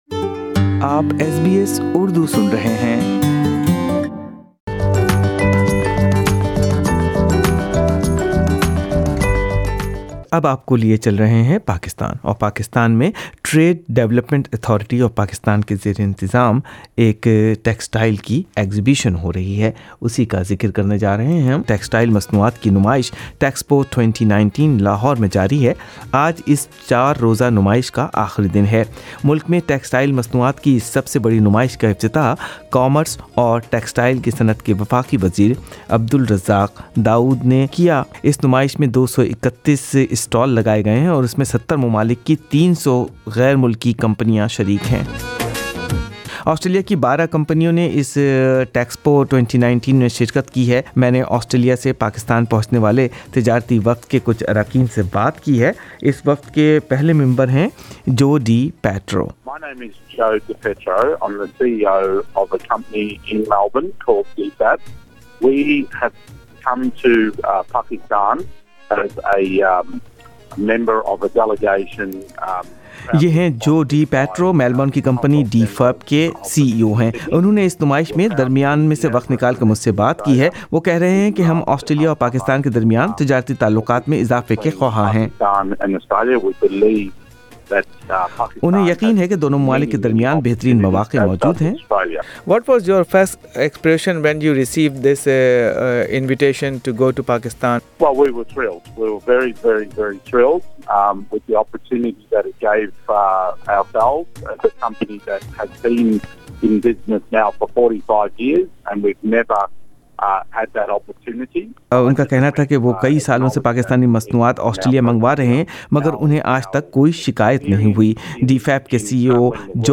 ان خیالات کا اظہار لاہور میں ہونے والی پاکستانی ٹیکسٹائیل کی سب سے بڑی نمائیش ٹیکسپو میں شرکت کرنے والے آسٹریلین وفد کے اراکین نے کیا۔
سنئیے آسٹریلین کمپنیوں کے کچھ اراکین سے بات چیت۔